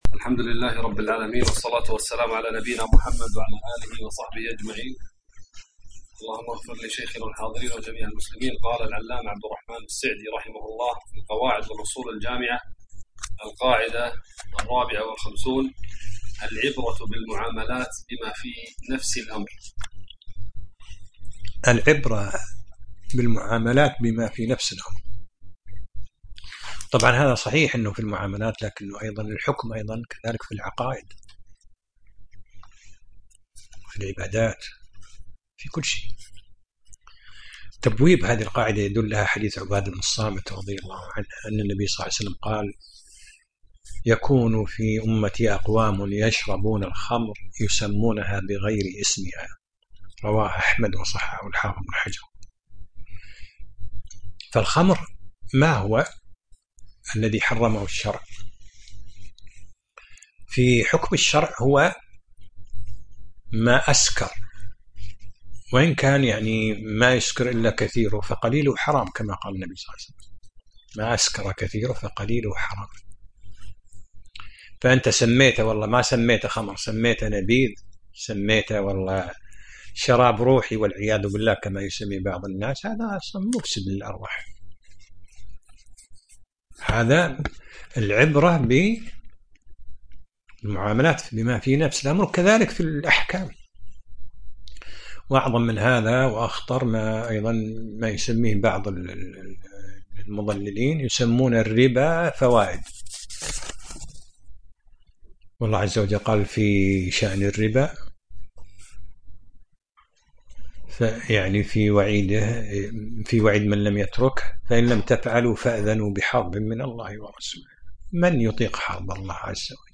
الدرس السابع عشر : من القاعدة 54 إلى القاعدة 56